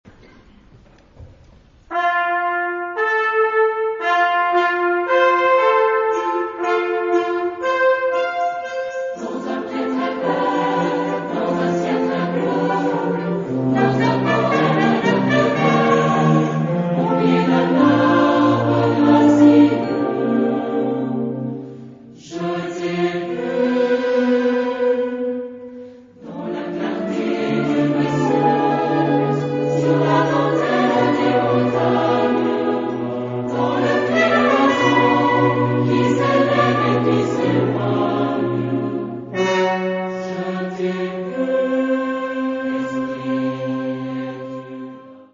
Genre-Style-Form: Secular ; Partsong
Mood of the piece: poetic
Type of Choir: SATB  (4 mixed voices )
Instrumentation: Wind ensemble
Tonality: C major